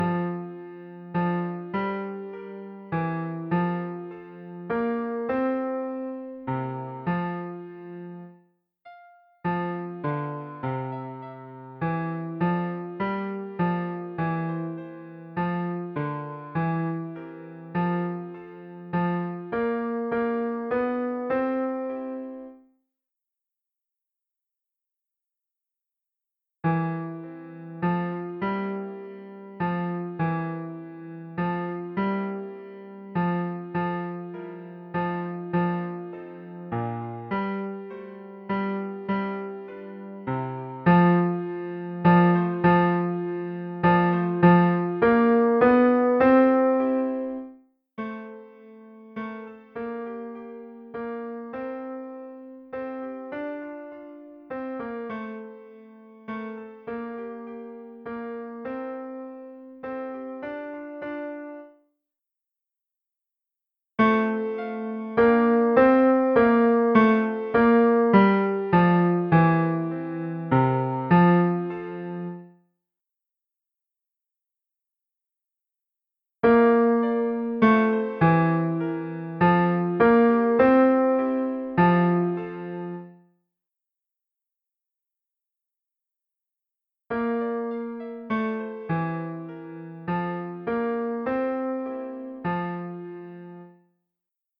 Basses
viens_dans_ce_sejour_basses.mp3